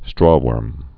(strôwûrm)